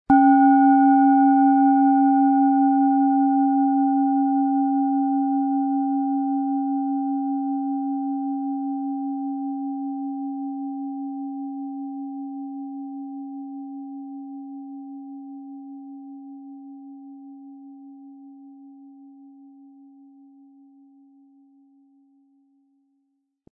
Planetenschale®
Das ist eine nach uralter Tradition von Hand getriebene Pluto Planetenschale.Weitergegebenes Know-how in kleinen Manufakturen, die seit Jahrhunderten Klangschalen herstellen, machen diese Klangschalen so unnachahmlich.
• Mittlerer Ton: Tageston
PlanetentönePluto & Tageston
SchalenformBihar
MaterialBronze